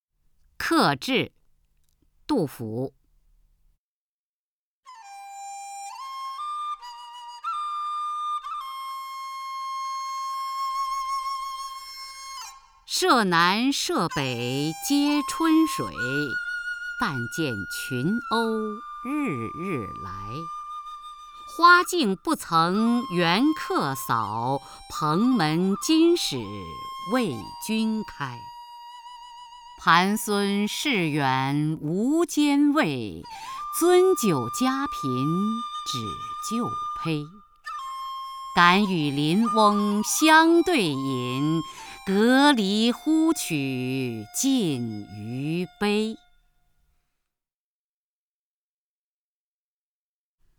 雅坤朗诵：《客至》(（唐）杜甫) (右击另存下载) 舍南舍北皆春水，但见群鸥日日来。